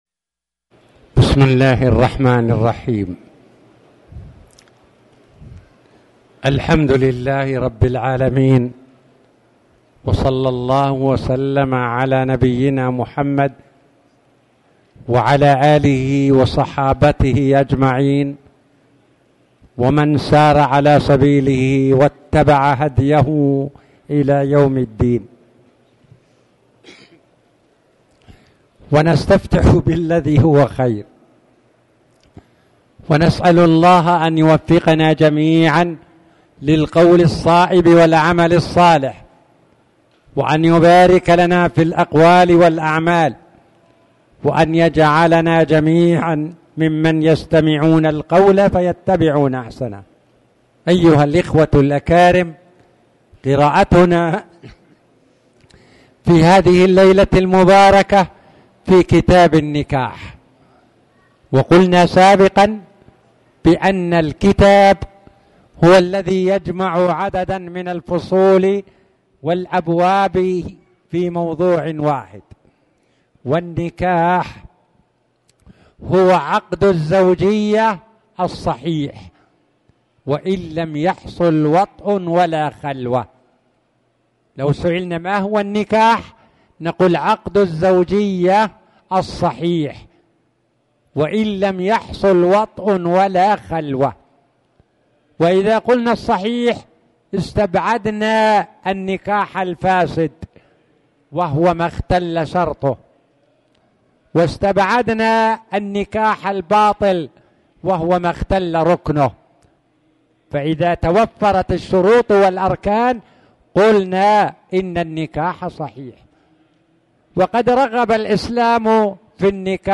تاريخ النشر ٨ صفر ١٤٣٨ هـ المكان: المسجد الحرام الشيخ